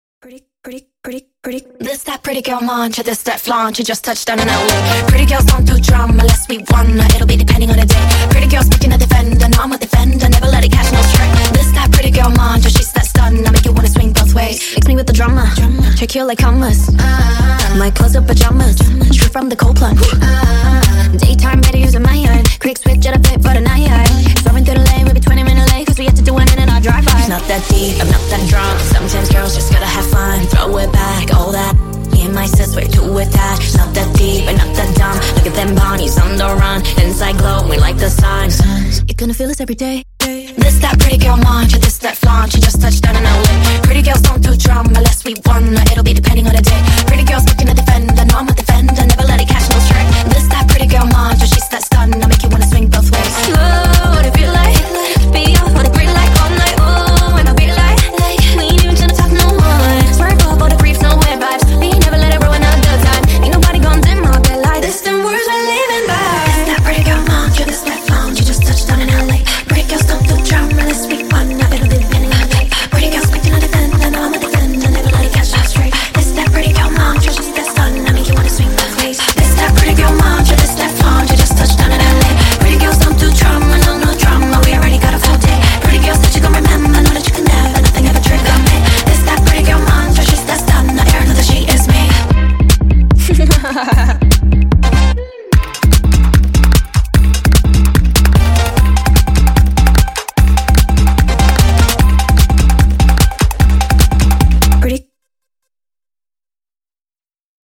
Скачать музыку / Музон / Speed Up